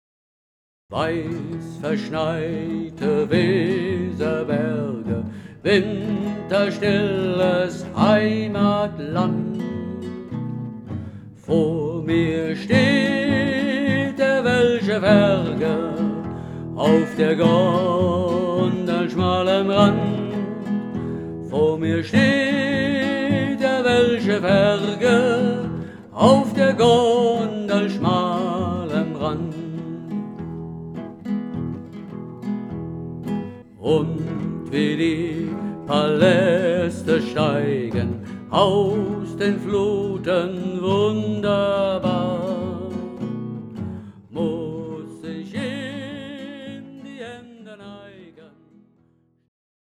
Ich teilte die Melodie in zwei Teile auf.